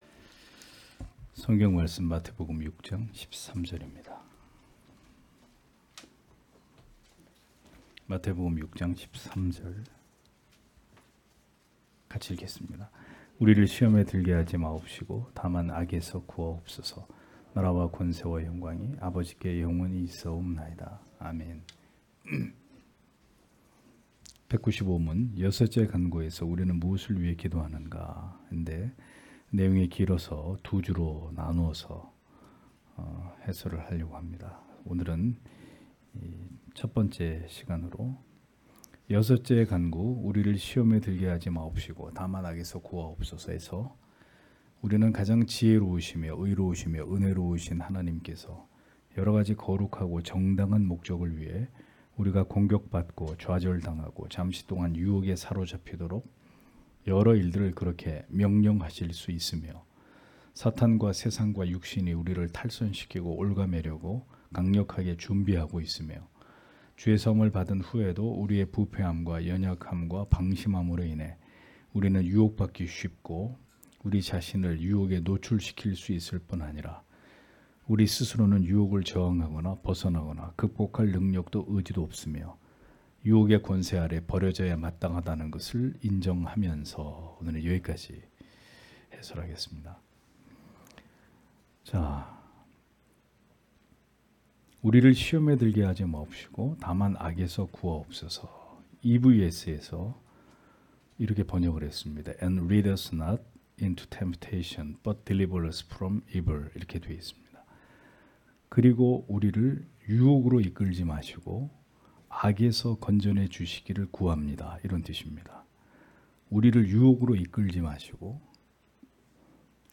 주일오후예배 - [웨스트민스터 대요리문답 해설 195 (1) ] 195문) 여섯째 기원에서 우리는 무엇을 위해 기도하는가?
* 설교 파일을 다운 받으시려면 아래 설교 제목을 클릭해서 다운 받으시면 됩니다.